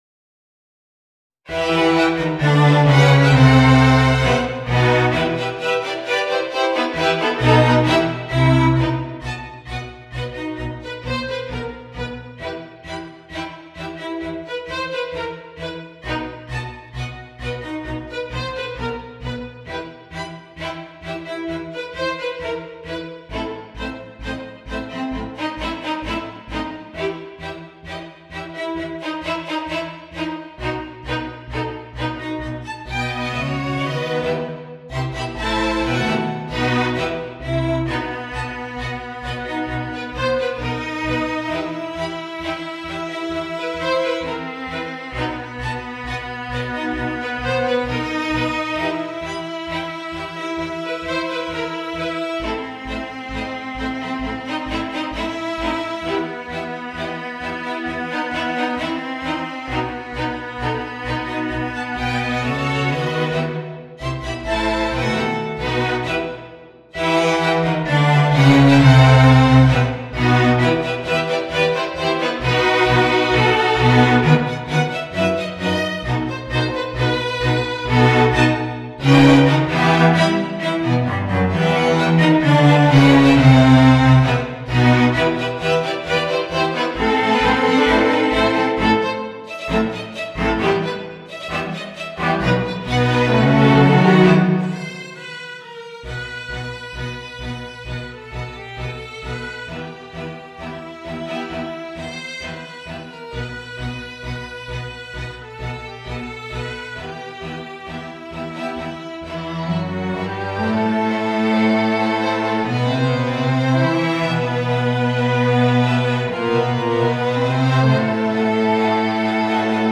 弦楽三重奏+ピアノ